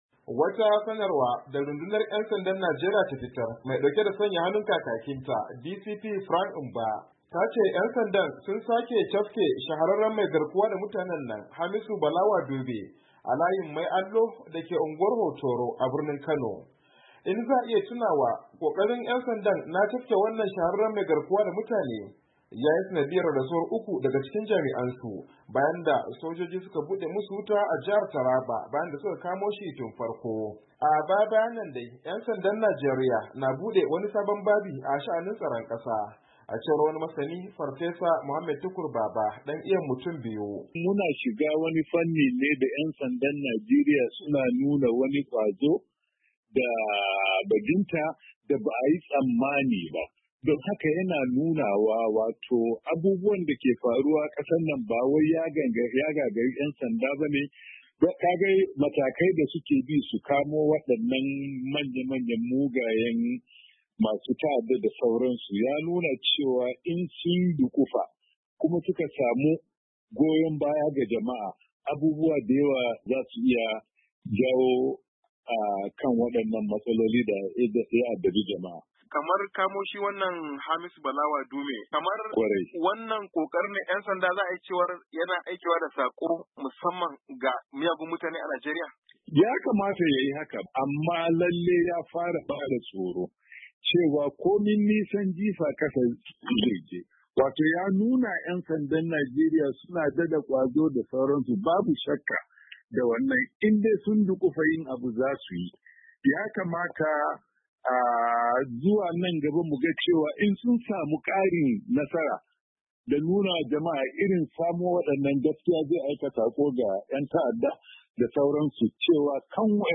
Ga dai rahoton wakilinmu